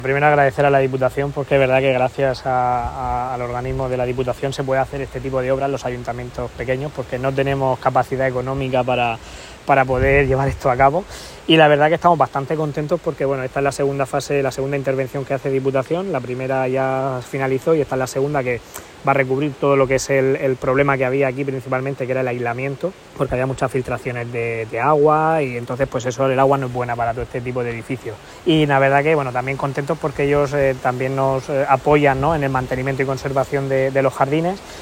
Alcalde-Jacarilla.mp3